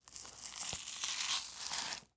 Звук очистки дыни от кожуры